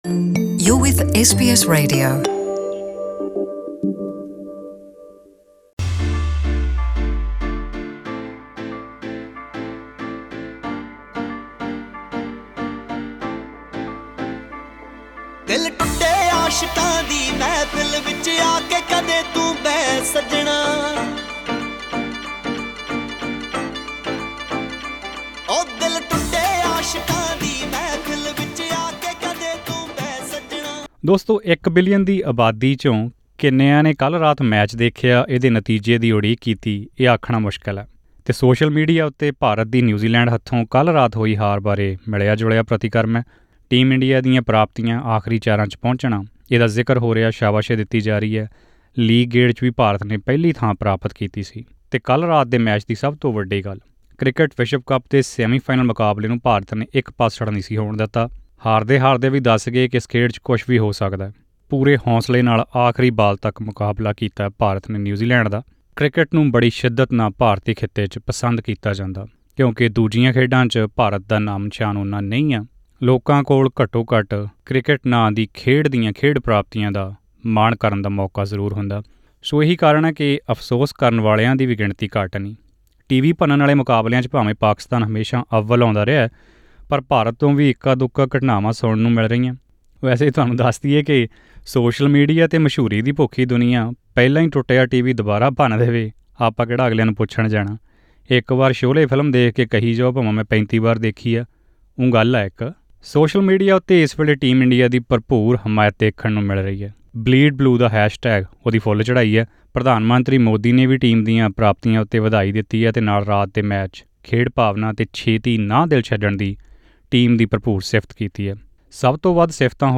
ਇੱਕ 'ਹਲਕੀ-ਫੁਲਕੀ' ਆਡੀਓ ਰਿਪੋਰਟ....